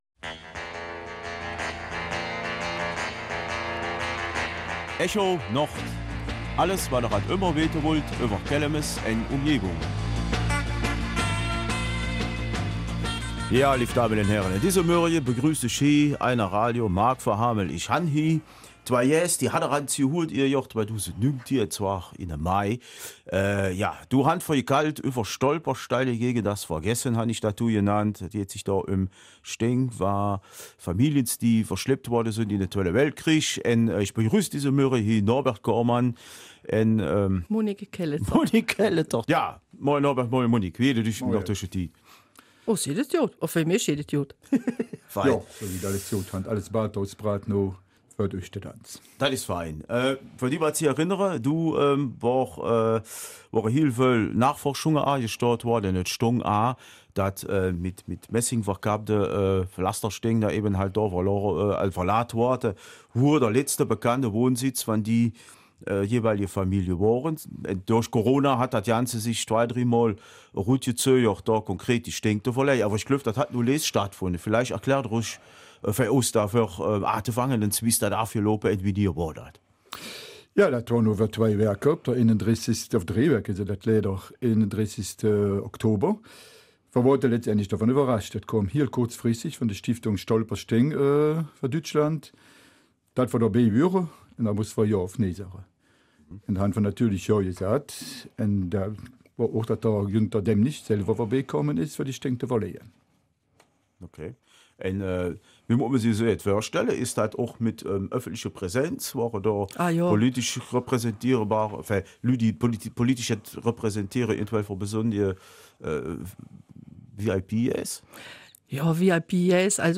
Kelmiser Mundart: Verlegung der Stolpersteine gegen das Vergessen in Walhorn
Nach Corona konnten auch in Walhorn kürzlich mehrere Steine verlegt werden. Darüber und über weitere Recherchen berichten die beiden Studiogäste in der Nachfolge am kommenden Sonntag.